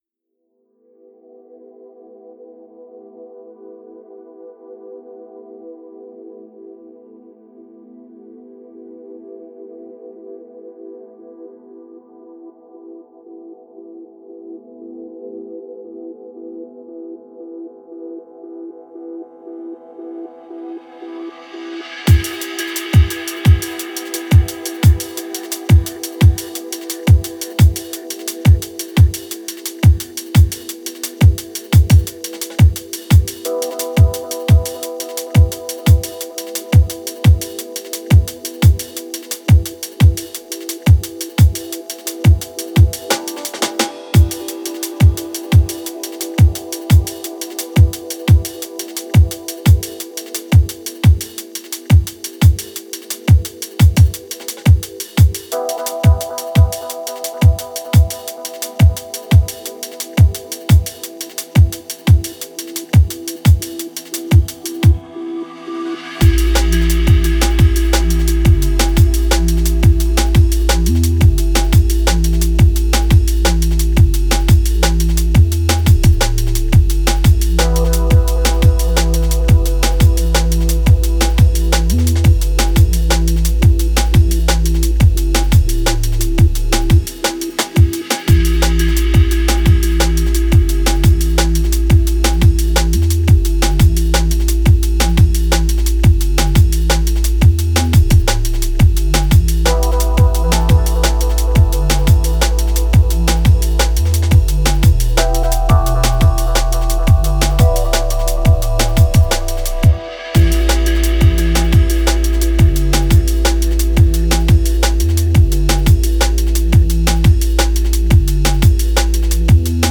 Style: Drum And Bass